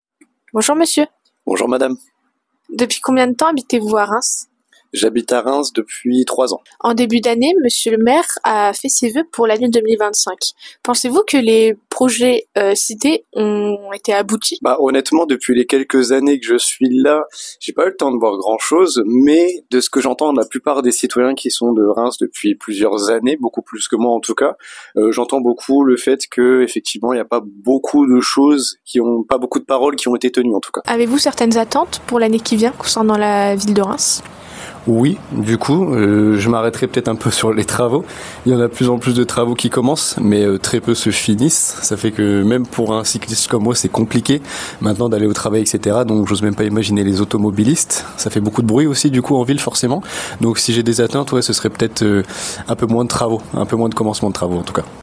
micro-trottoir